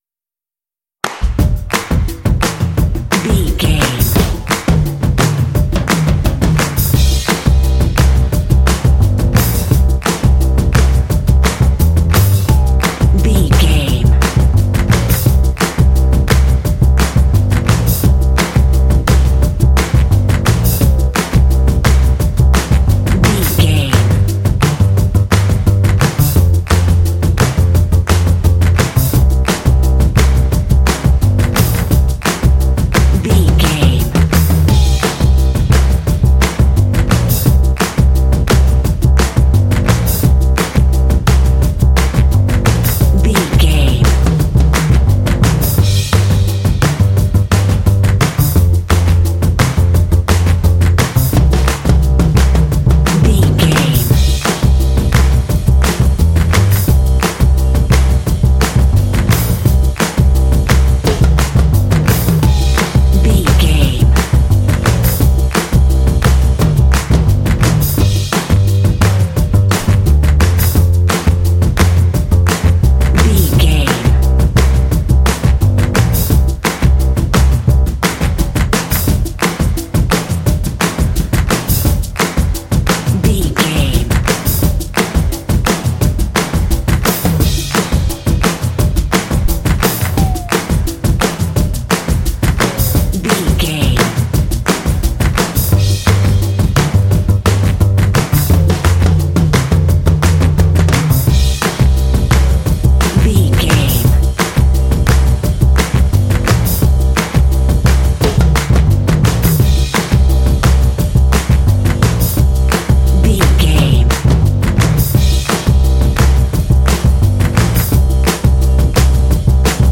Ionian/Major
groovy
bouncy
cheerful/happy
drums
percussion
bass guitar
blues
jazz